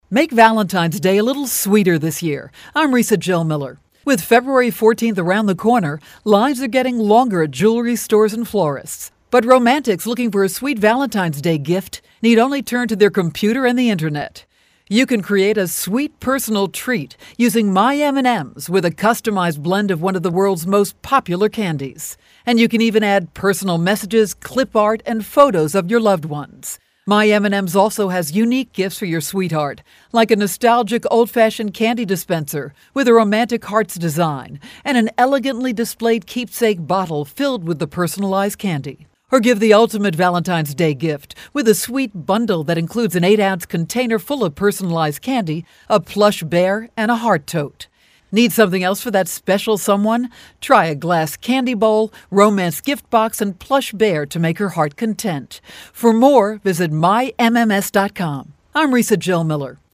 February 7, 2013Posted in: Audio News Release